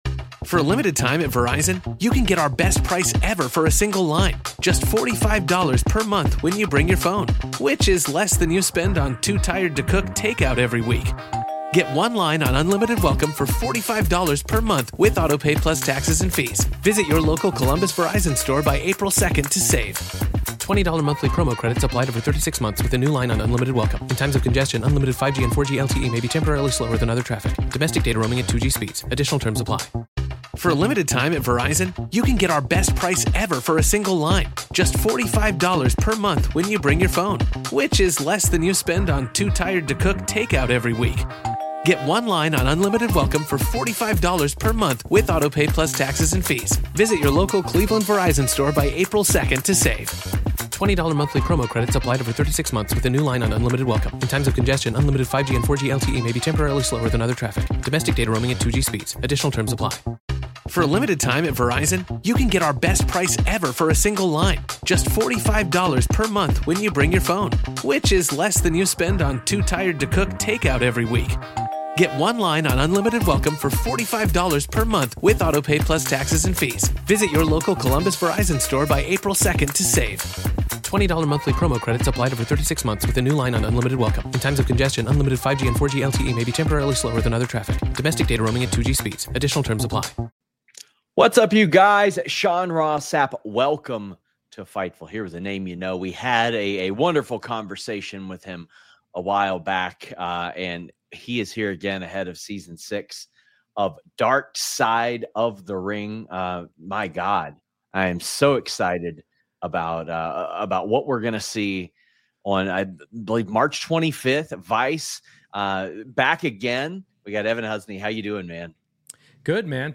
Shoot Interviews Mar 25